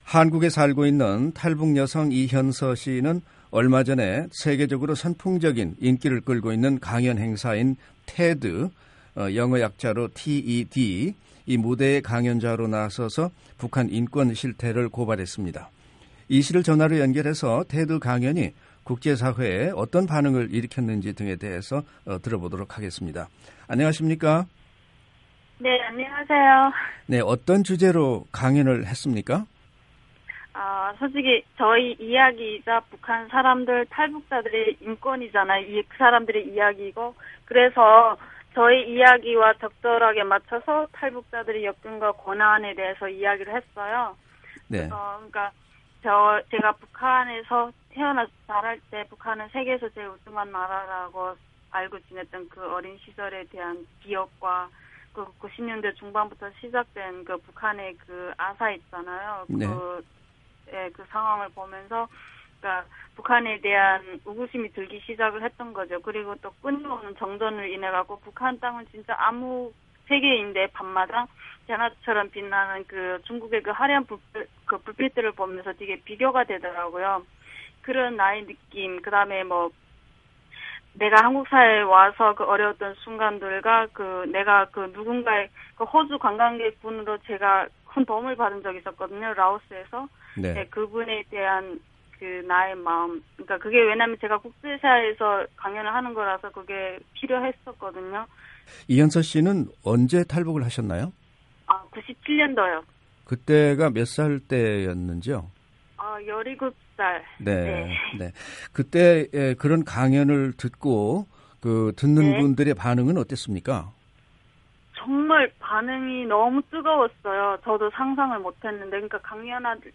[인터뷰] 탈북 여성 '테드' 강연, 북한인권 실태 알려